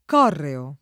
— acc. sull’-o- più freq. tra i legali, conforme al lat. correus [